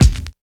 99 KICK 3.wav